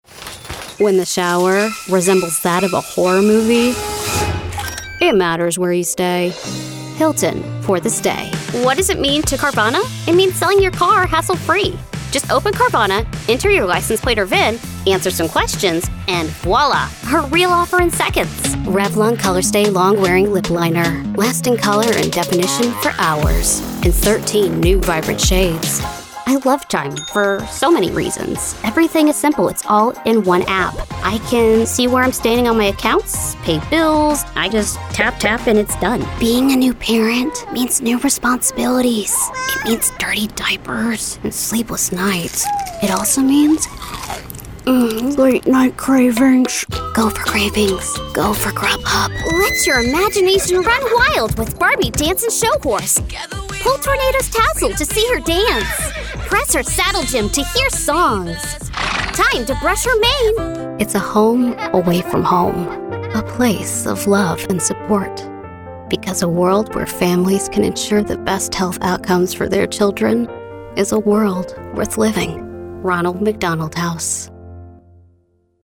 Commercial
My voice has been described as sassy and quirky, but I can still be warm and comforting.